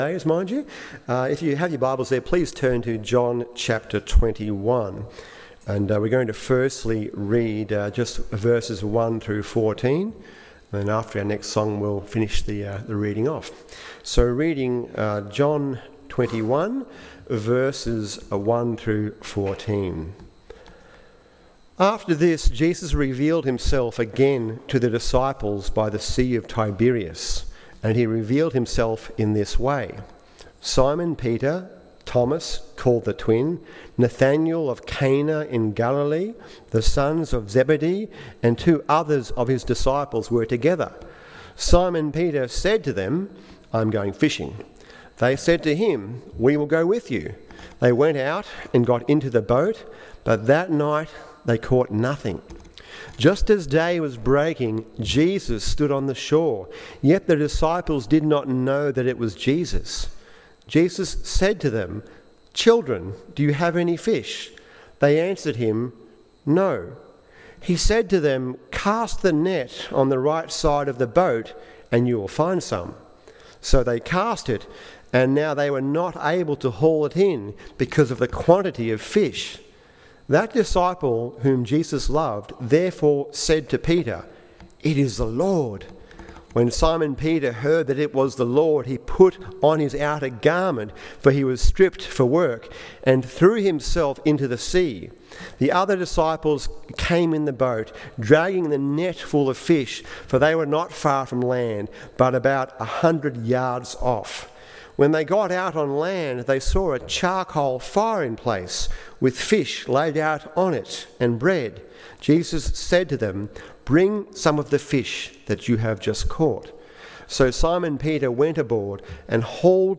Fragile Fishermen Made Fishers Of Men PM Easter Sunday Service